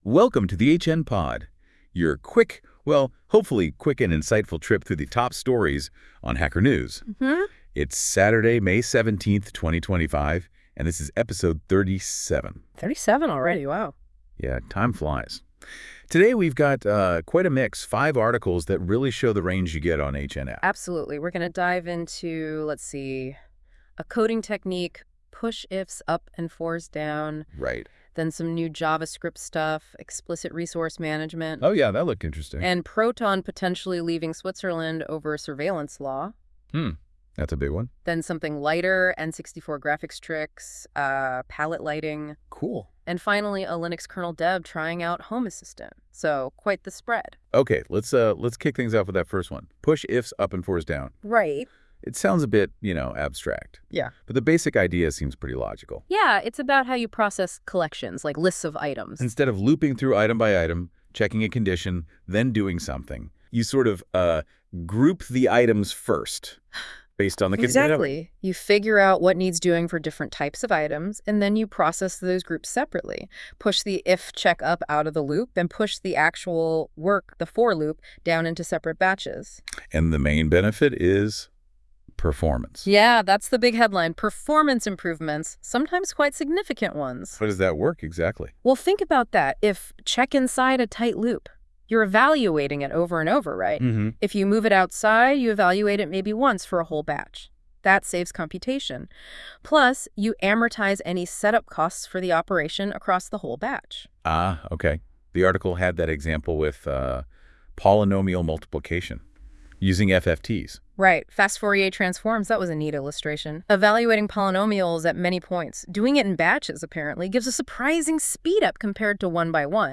This episode covers: Push Ifs Up and Fors Down [discussion] JavaScript's New Superpower: Explicit Resource Management [discussion] Proton threatens to quit Switzerland over new surveillance law [discussion] Palette lighting tricks on the Nintendo 64 [discussion] A kernel developer plays with Home Assistant [discussion] This episode is generated by 🤖 AI.